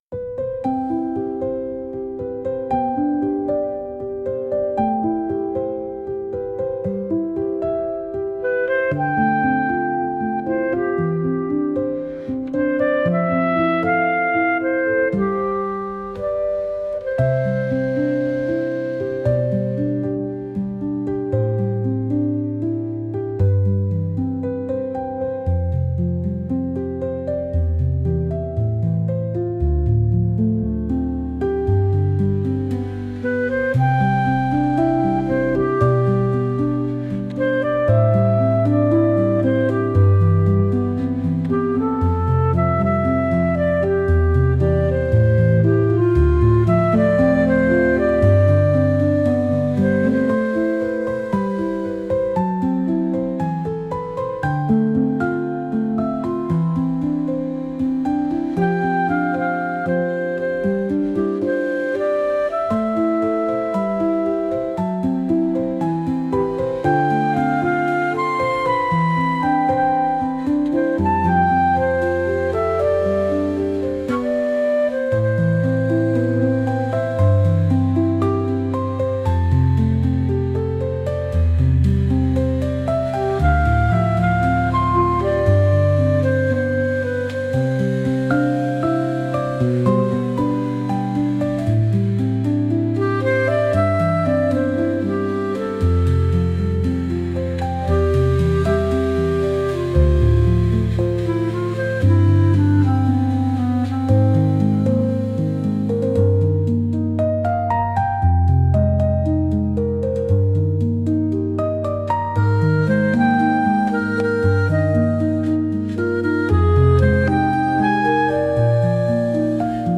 穏やかなクラリネットとハープの曲です。